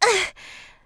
Shua_voice_damage.wav